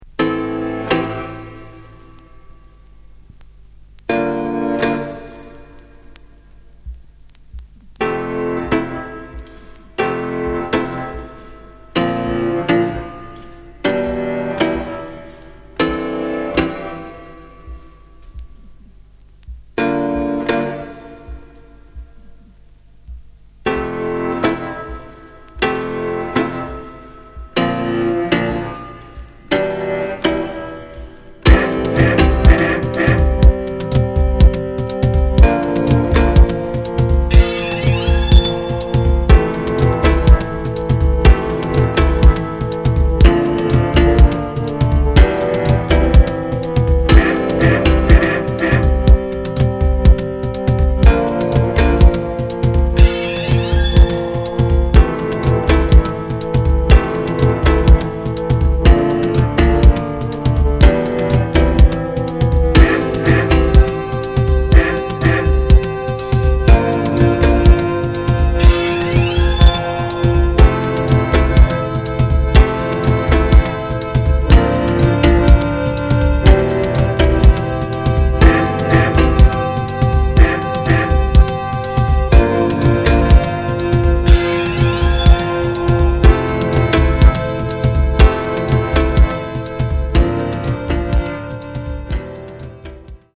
宅録　ミニマル